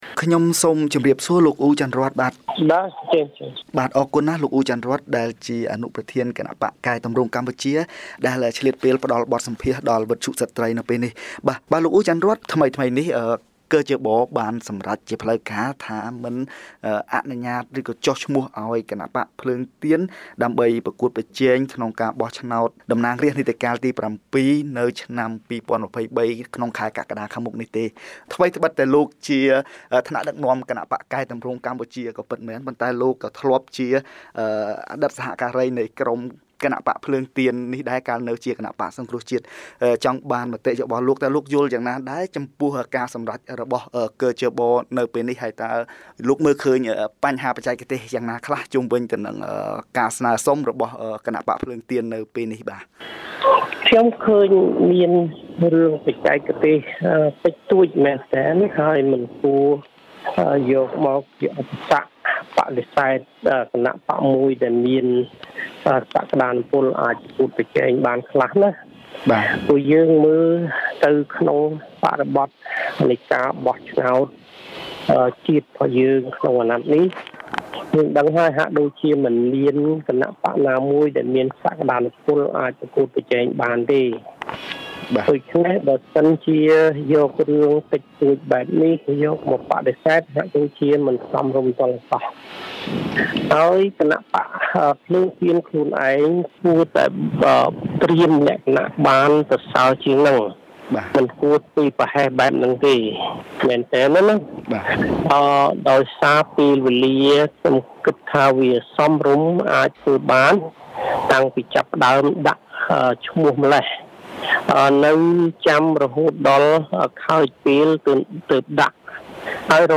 (បទសម្ភាស)